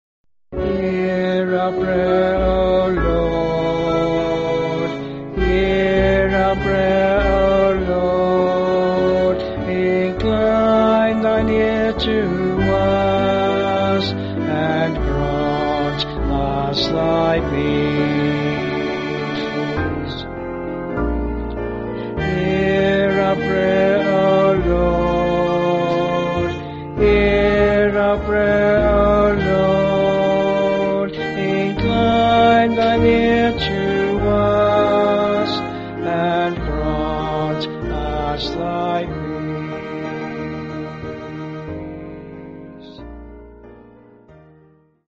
Vocals and Instrumental